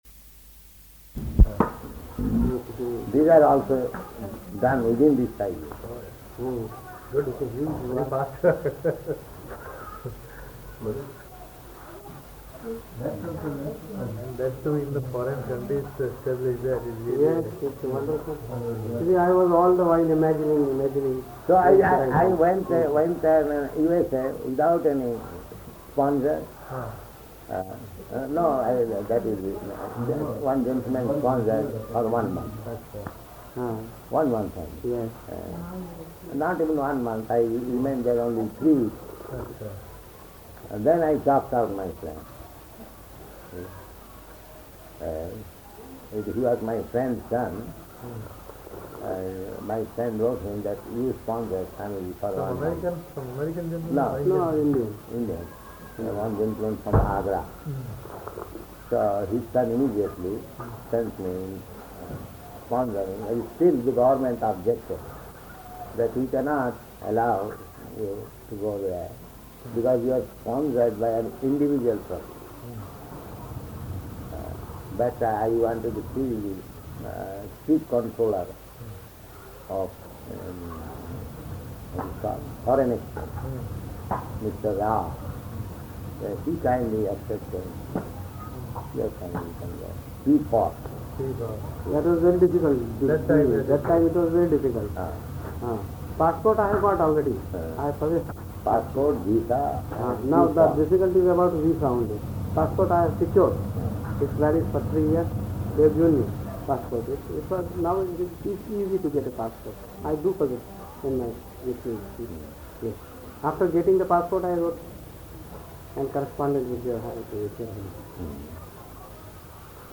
Room Conversation
Room Conversation --:-- --:-- Type: Conversation Dated: November 7th 1970 Location: Bombay Audio file: 701107R1-BOMBAY.mp3 Prabhupāda: These are also done within these five years.